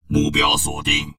文件 文件历史 文件用途 全域文件用途 Enjo_skill_02_2.ogg （Ogg Vorbis声音文件，长度1.2秒，121 kbps，文件大小：17 KB） 源地址:地下城与勇士游戏语音 文件历史 点击某个日期/时间查看对应时刻的文件。